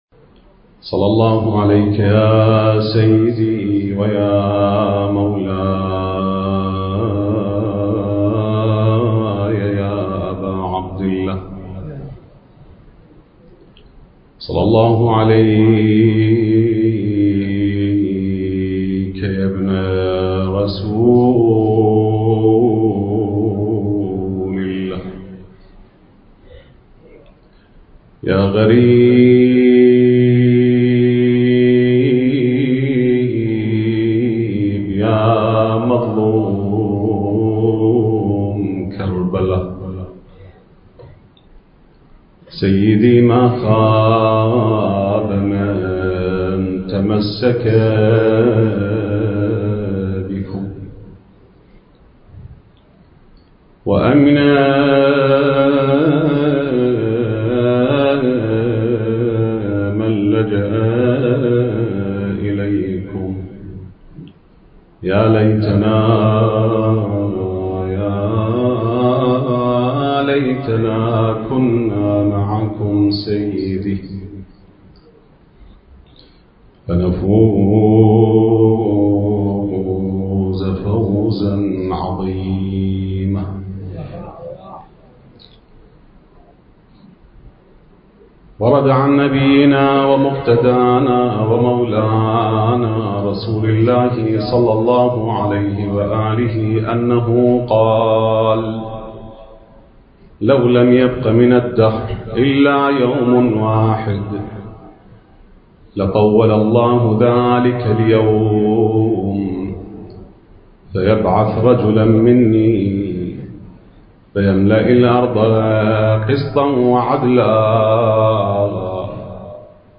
المكان: العزاء المركزي في قضاء عفك - مجلس أبي طالب (عليه السلام) التاريخ: 2022